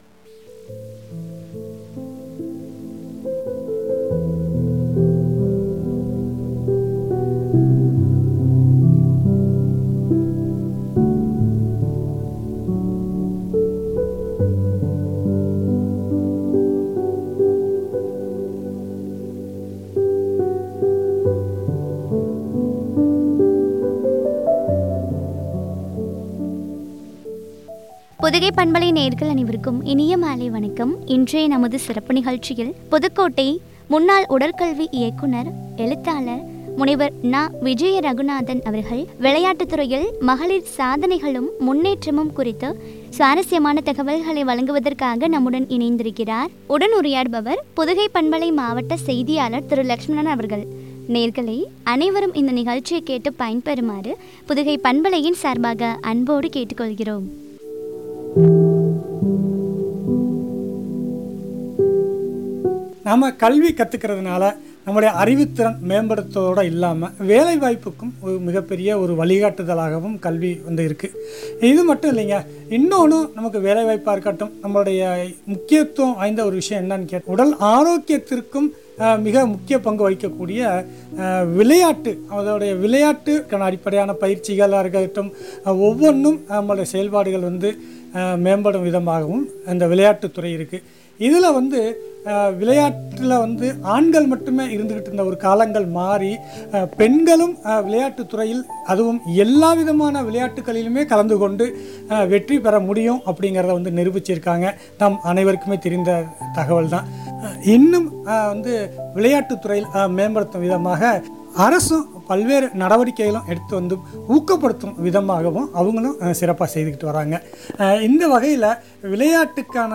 விளையாட்டுத் துறையில் மகளிர் சாதனைகளும் முன்னேற்றமும் குறித்து வழங்கிய உரையாடல்.